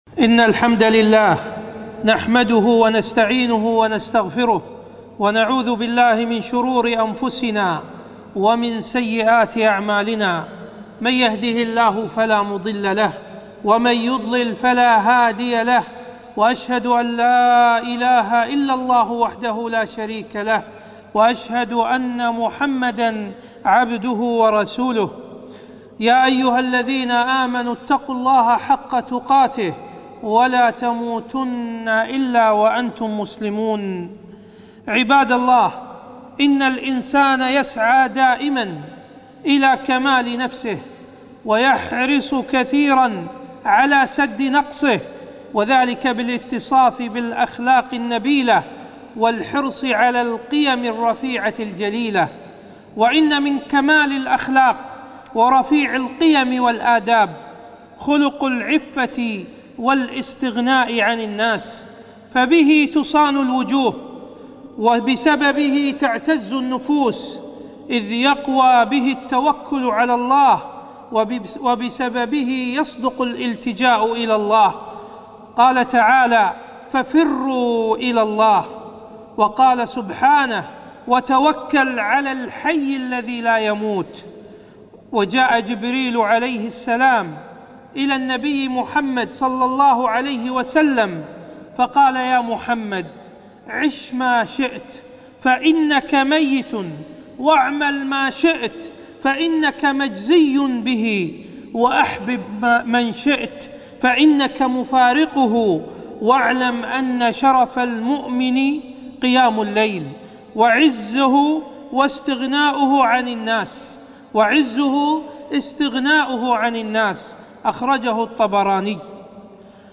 خطبة - عزة النفس والاستغناء عن الناس